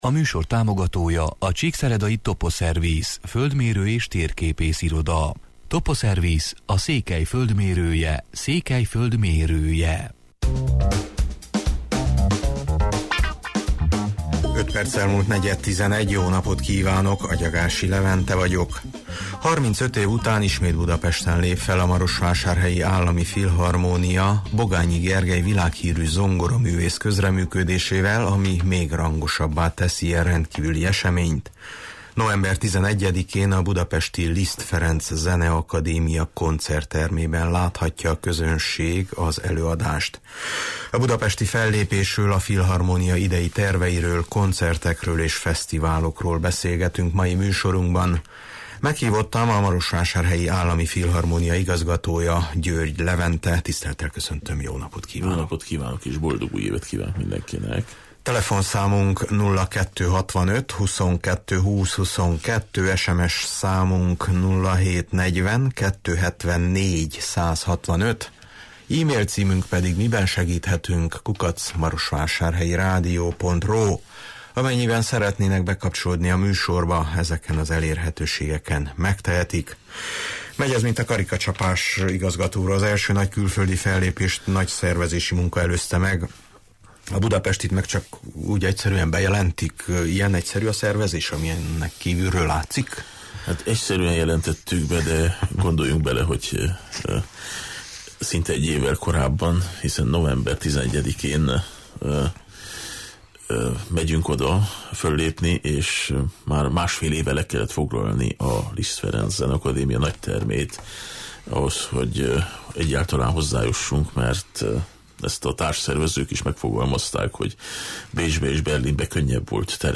A budapesti fellépésről, a filharmónia idei terveiről, koncertekről és fesztiválokról beszélgetünk mai műsorunkban.